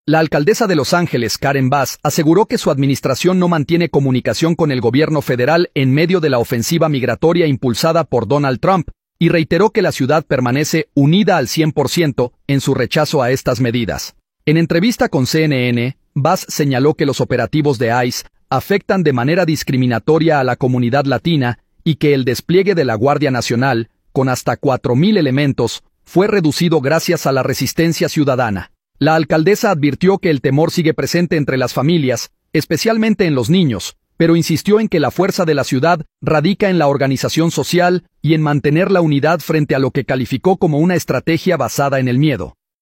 La alcaldesa de Los Ángeles, Karen Bass, aseguró que su administración no mantiene comunicación con el Gobierno Federal en medio de la ofensiva migratoria impulsada por Donald Trump, y reiteró que la ciudad permanece “unida al 100 por ciento” en su rechazo a estas medidas. En entrevista con CNN, Bass señaló que los operativos de ICE afectan de manera discriminatoria a la comunidad latina y que el despliegue de la Guardia Nacional, con hasta 4 mil elementos, fue reducido gracias a la resistencia ciudadana.